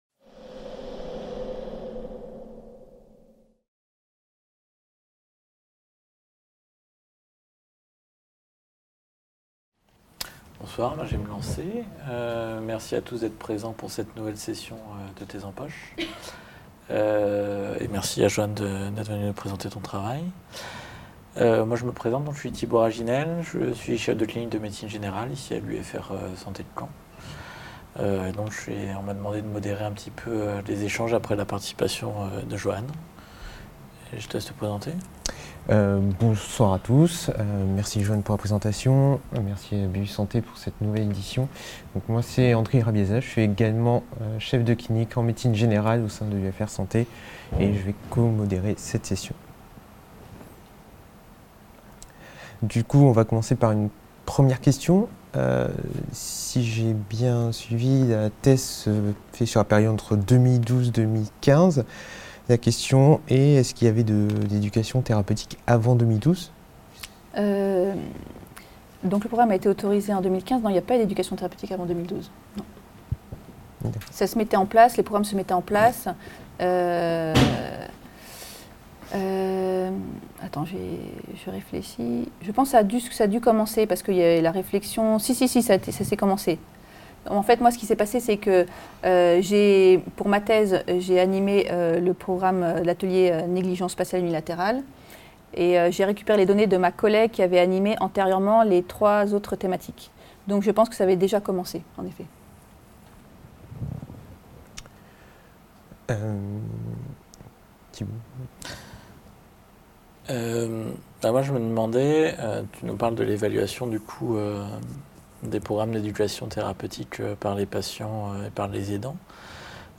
3B Échanges - Éducation thérapeutique du patient et troubles cognitivo-comportementaux post-AVC (tep2017) | Canal U